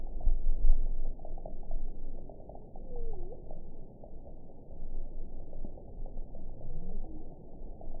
event 922137 date 12/27/24 time 05:43:29 GMT (11 months, 1 week ago) score 5.27 location TSS-AB06 detected by nrw target species NRW annotations +NRW Spectrogram: Frequency (kHz) vs. Time (s) audio not available .wav